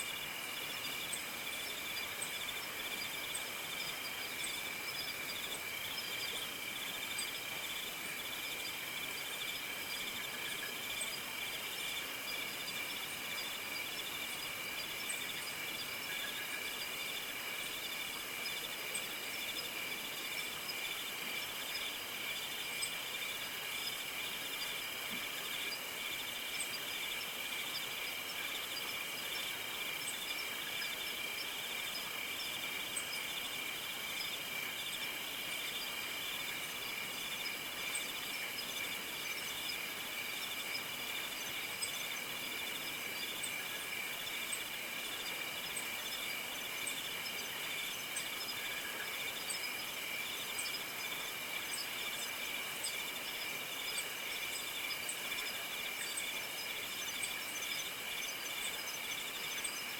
night.ogg